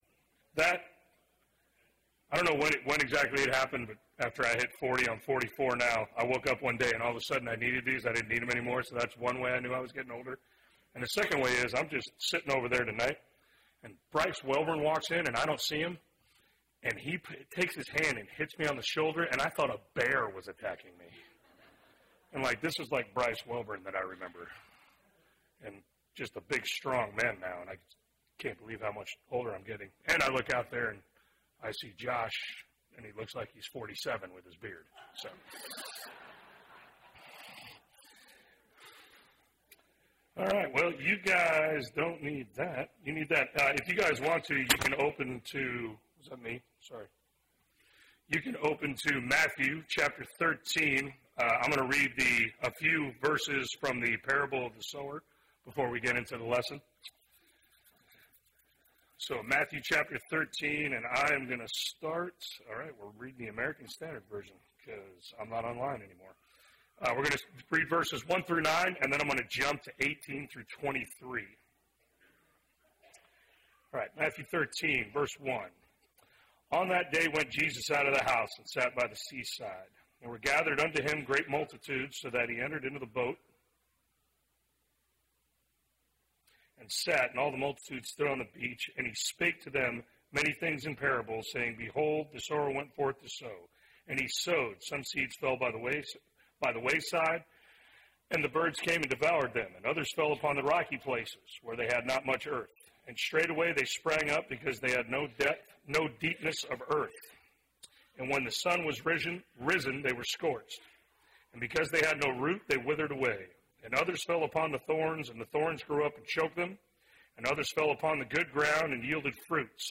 A Study of Selected Parables (2 of 7) – Bible Lesson Recording
Wednesday PM Bible Class